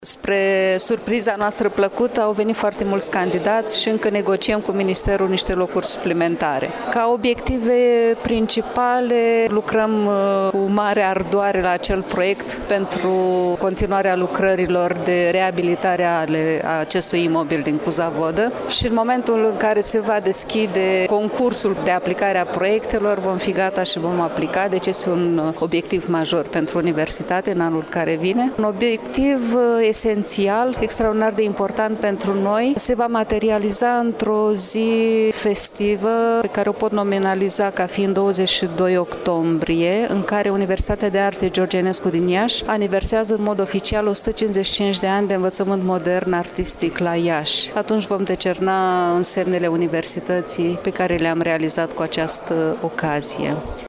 la deschiderea anului academic de la Universitatea de Arte din localitate